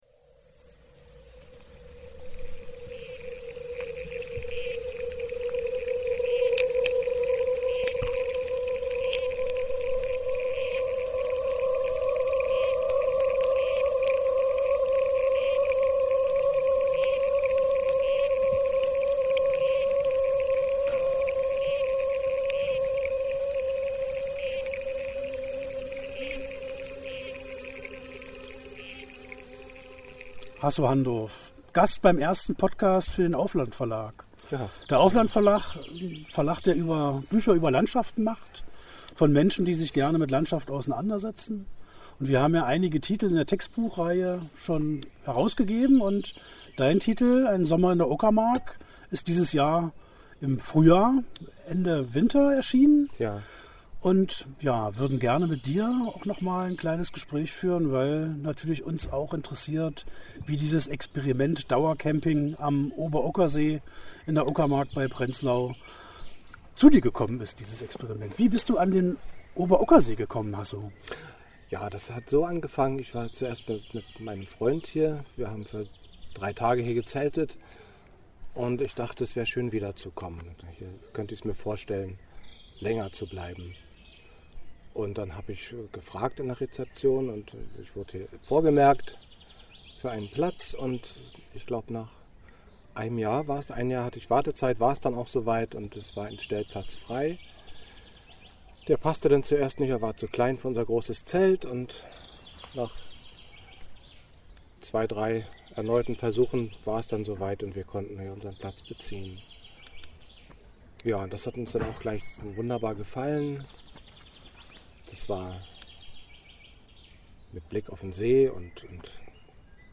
Das Gespräch
auf dem Campingplatz